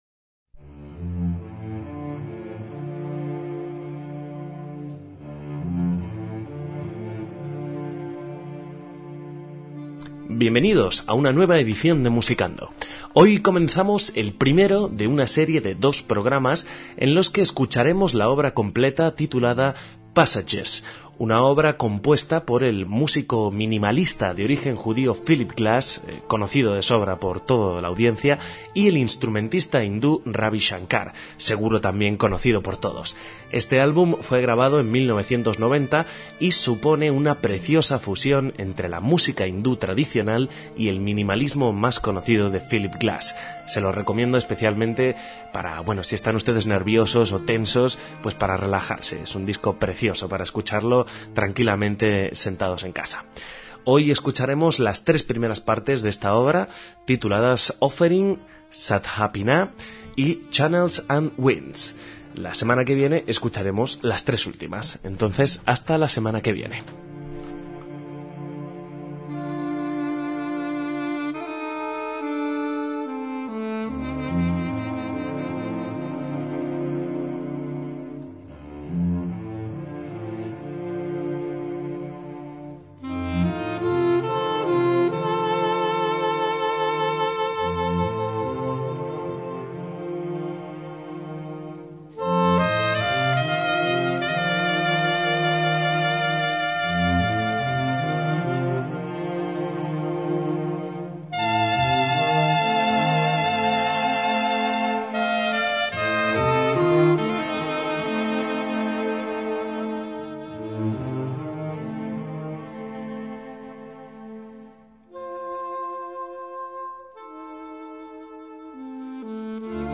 álbum de estudio de música de cámara